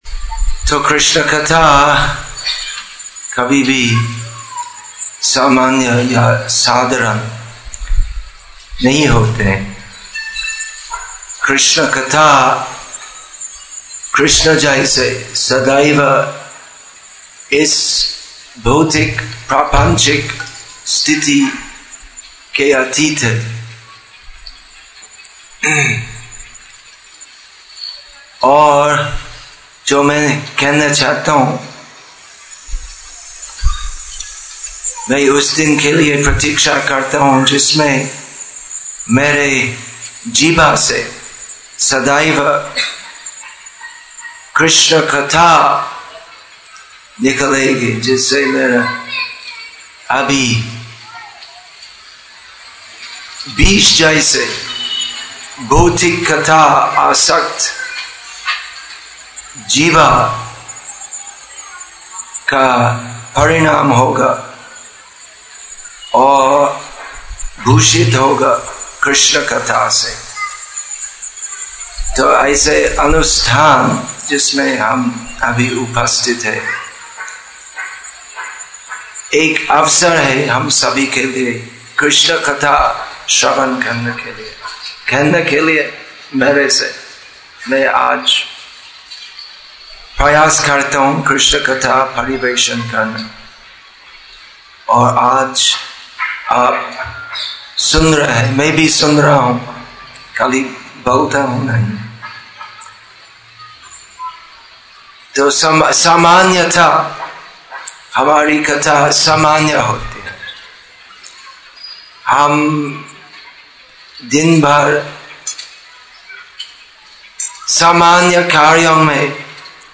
Assorted Lectures